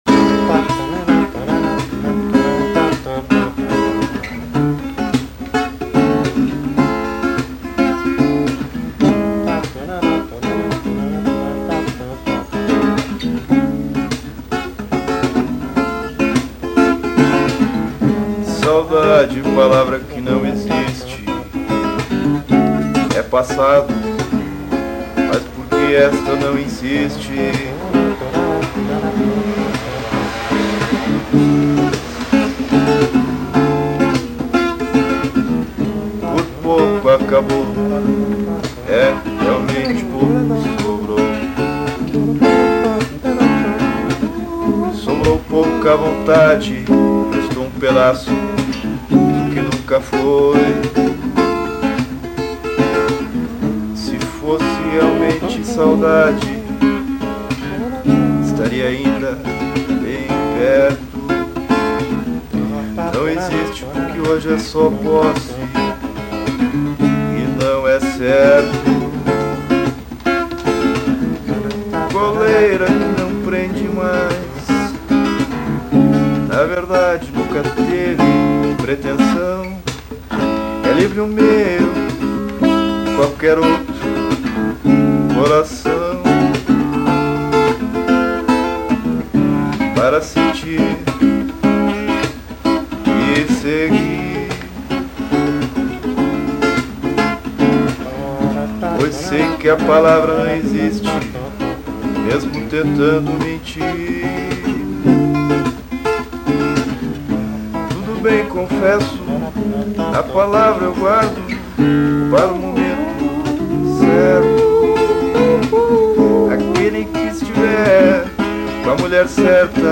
Musicas gravadas no momento de criação, realizadas no improviso, sem ensaio, sem estúdio ( faltam mais instrumentos em arranjos e ensaio, outras Letras melhores e mais importantes estão para terem acompanhamento de violão e ritmo, ou menos, ou mais, para virarem Novos Protótipos ou Esboços como base para Gravação )...